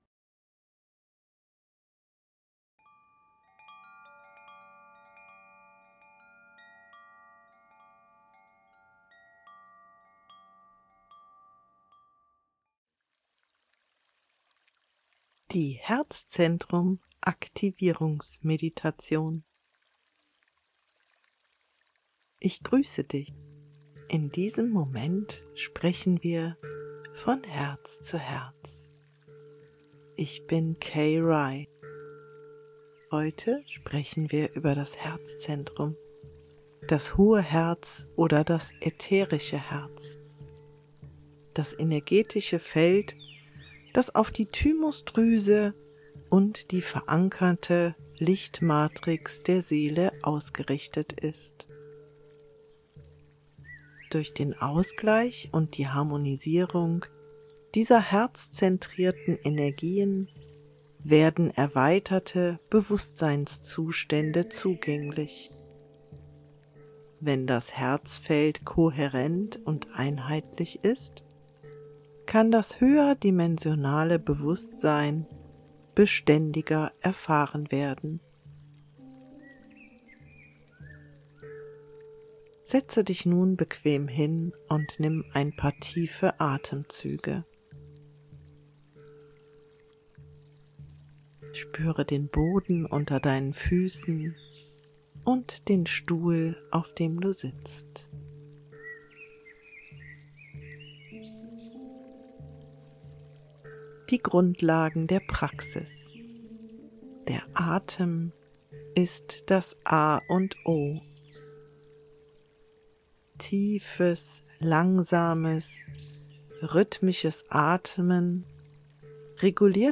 Klang-Meditationen